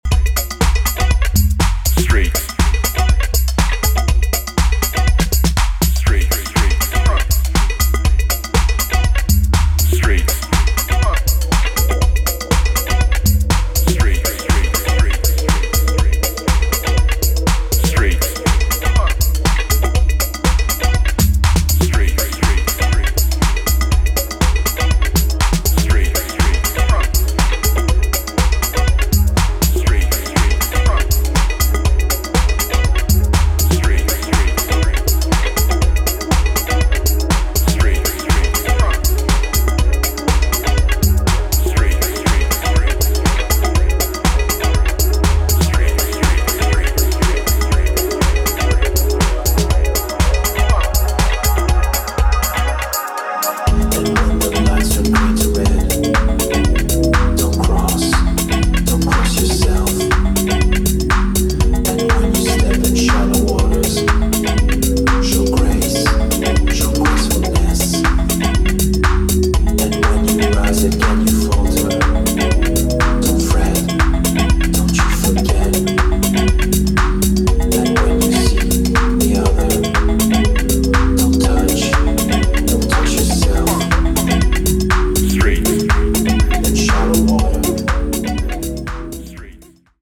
a deep, minimalist piece